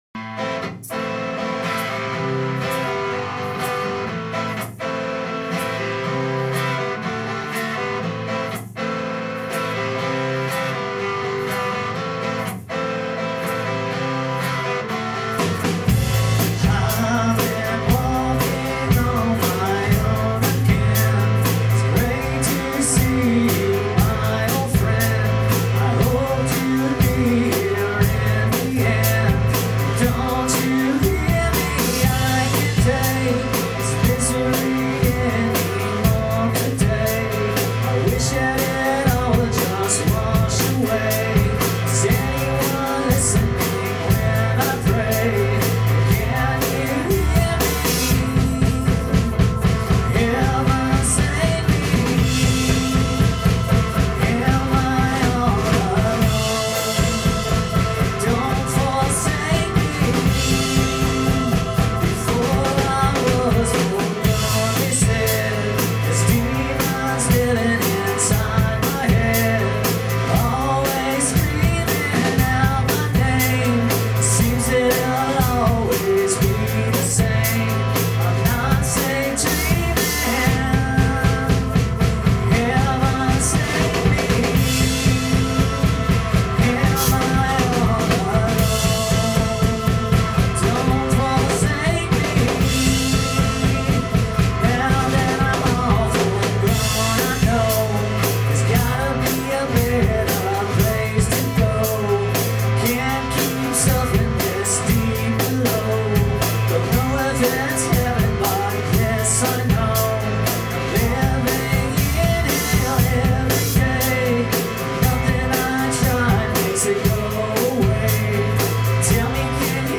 Full Band